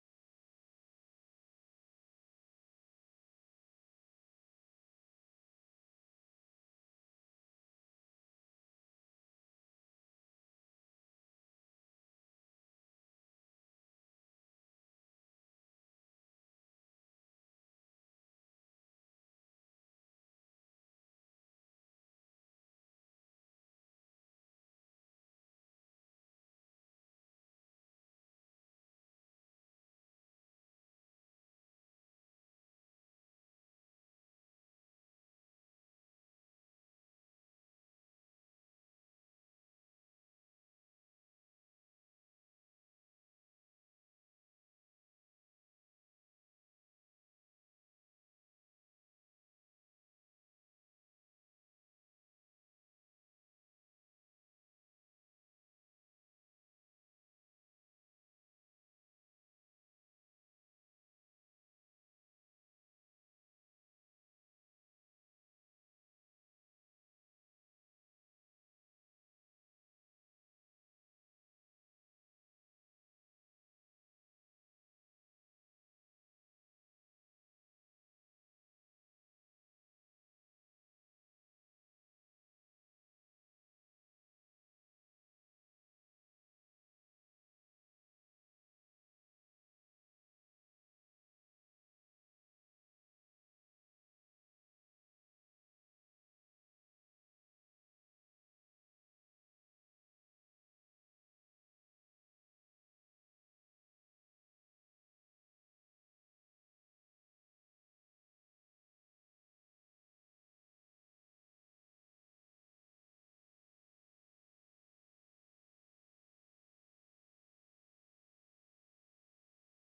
July 28 2024 Praise and Worship